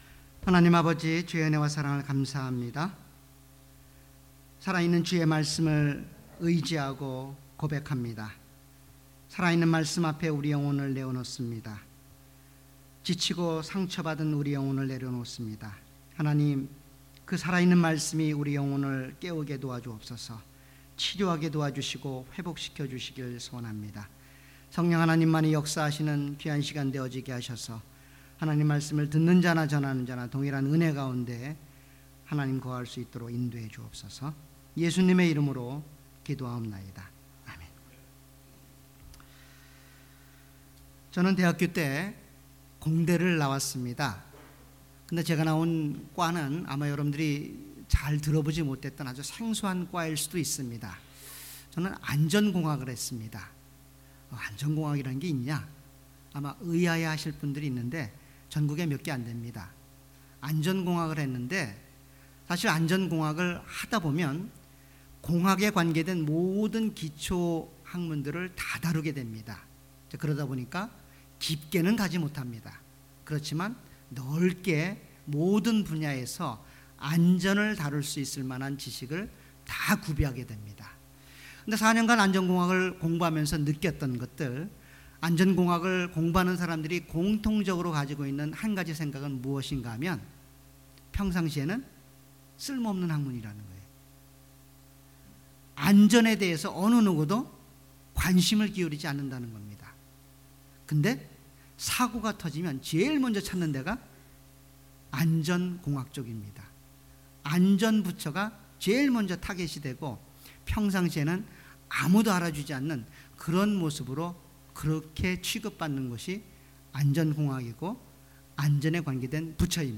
주일예배.Sunday